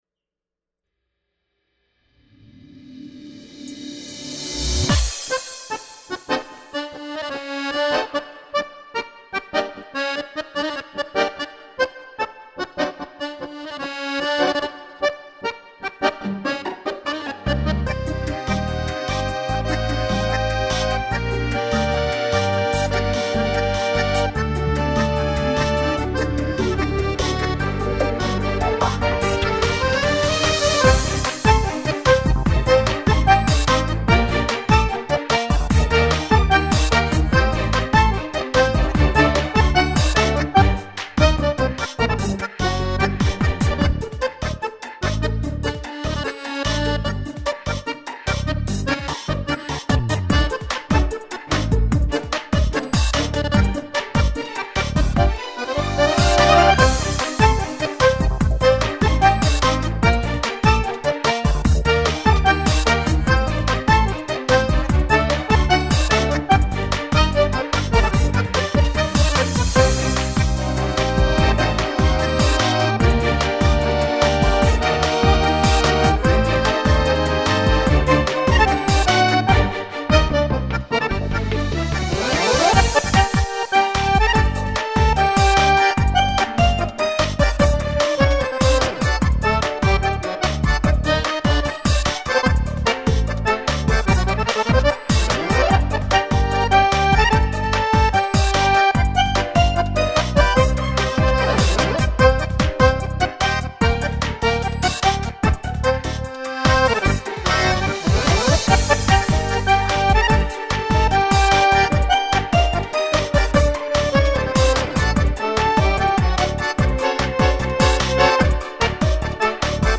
Фон - танго Компарсита.